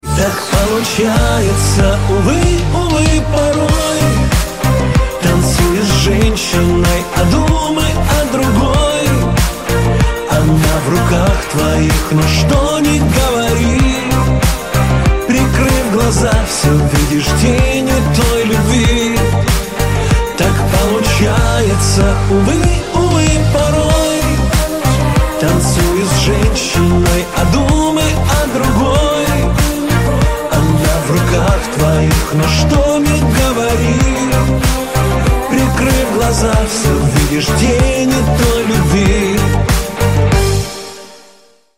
Поп Рингтоны
Скачать припев песни